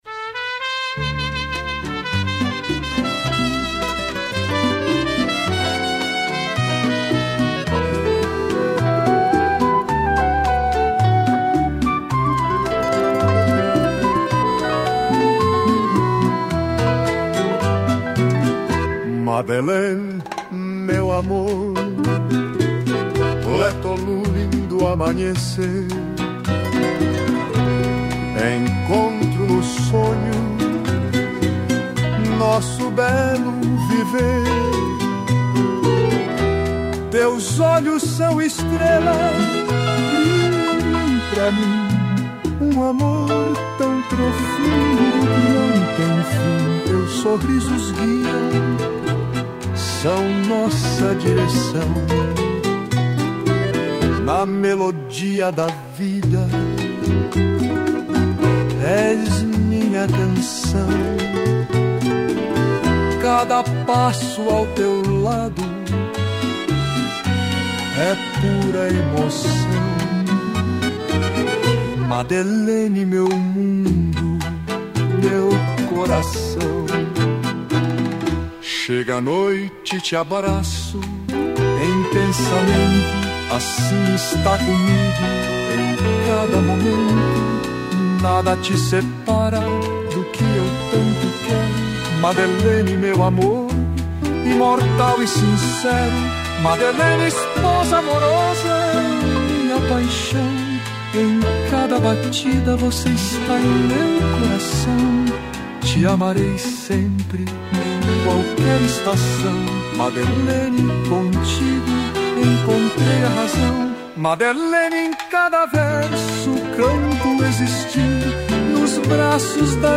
violão
piano e acordeão